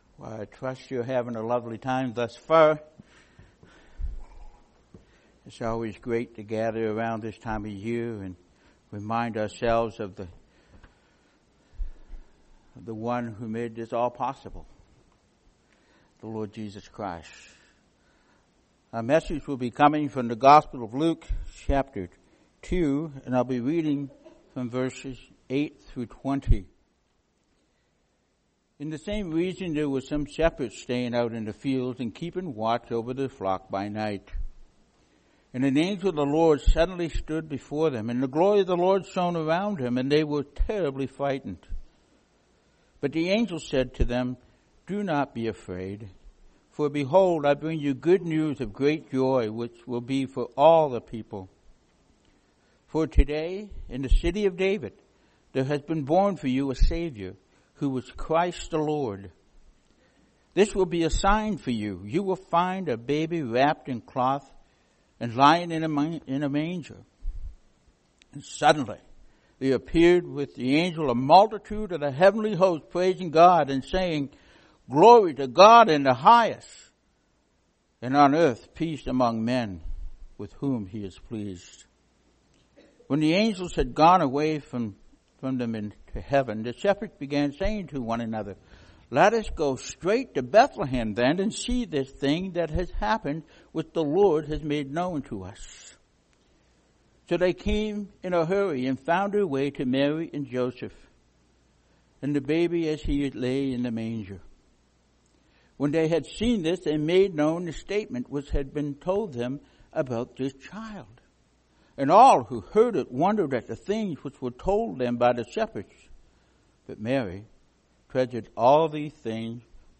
Christmas Eve Communion Service